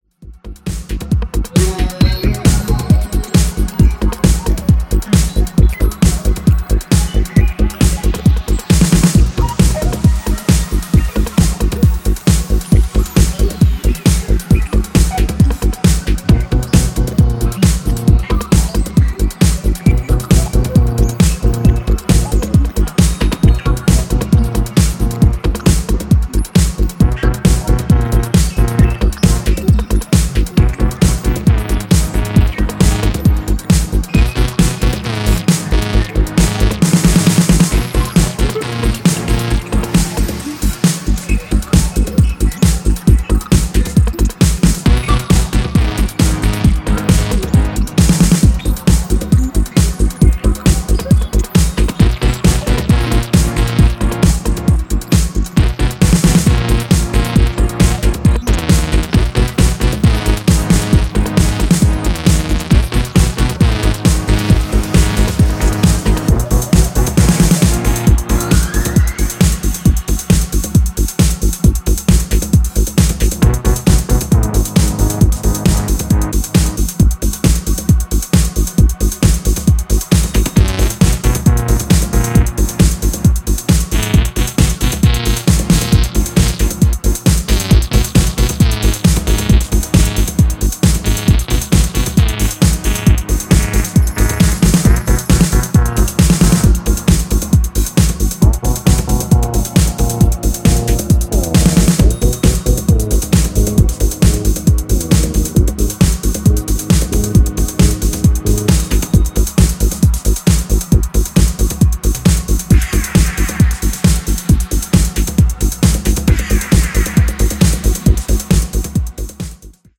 全編エレクトロ感覚もうっすらと感じさせるパンピン&ファンキーなテック・ハウスでこれはフロアをガツンと揺らせそう。